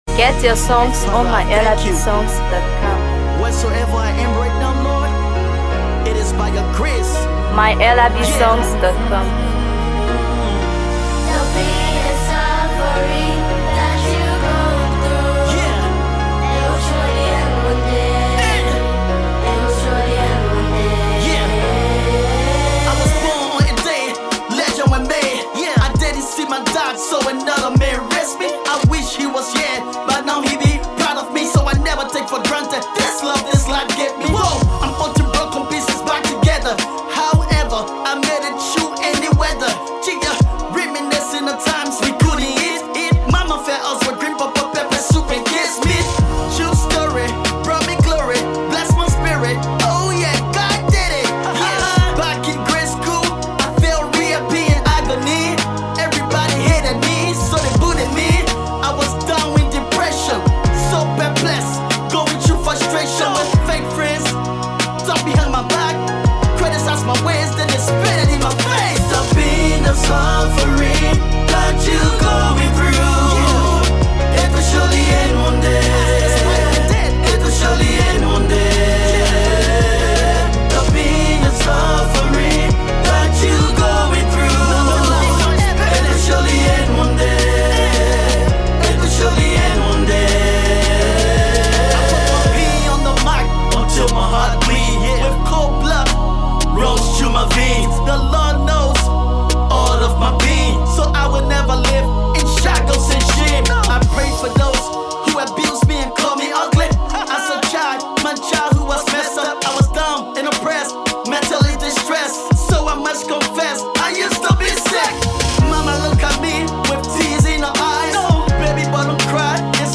GospelMusic
street gospel
infectious rhythm